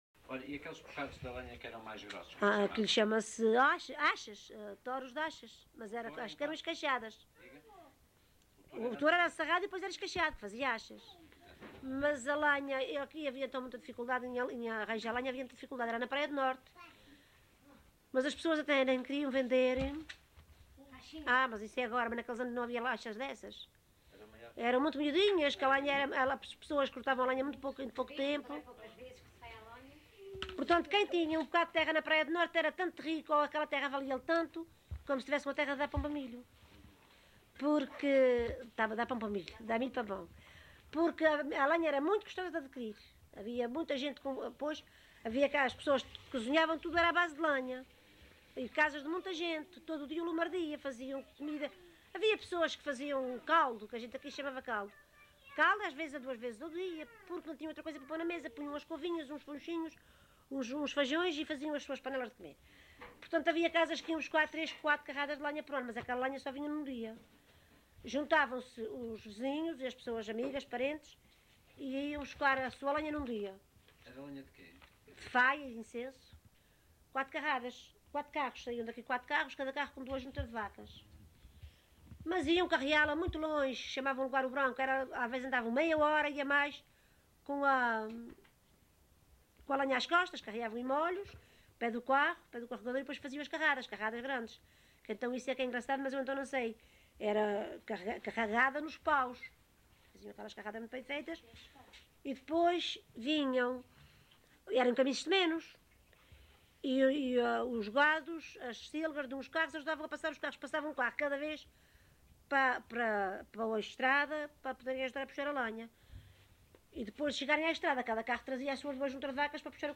LocalidadeCedros (Horta, Horta)